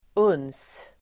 Uttal: [un:s]